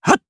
Siegfried-Vox_Attack2_jp.wav